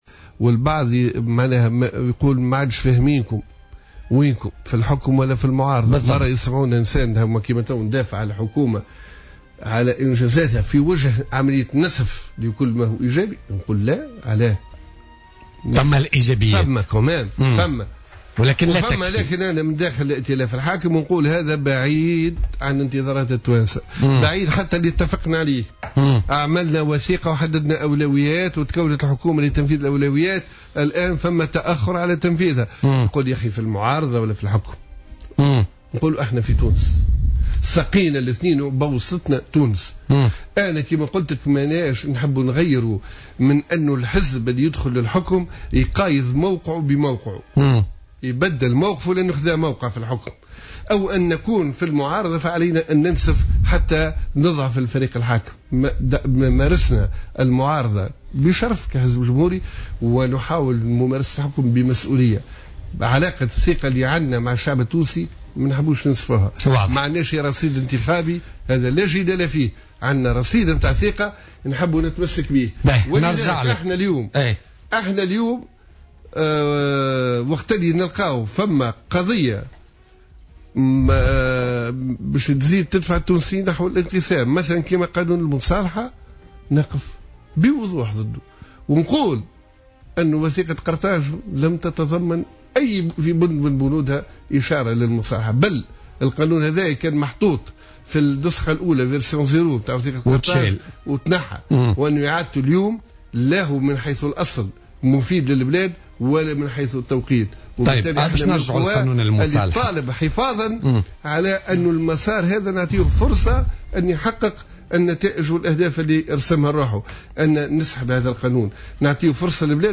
أكد الأمين العام للحزب الجمهوري عصام الشابي في مداخلة له في بولتيكا اليوم الأربعاء، معارضة حزبه لقانون المصالحة ووقوفه ضد تمريره لما قد يسببه من مزيد تقسيم التونسيين، وهو نفس الموقف الذي أبداه الحزب ضد هذا القانون حين تم طرحه سنة 2015.